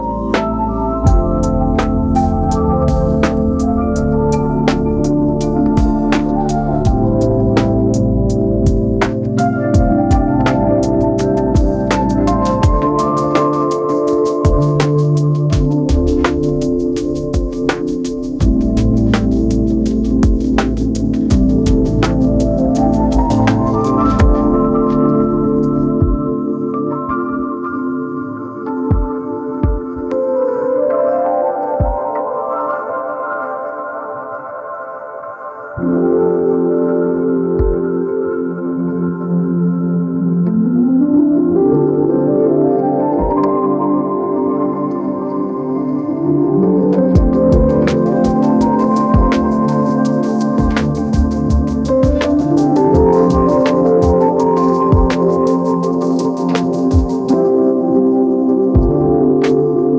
infinifi - infinifi plays gentle lofi music in the background indefinitely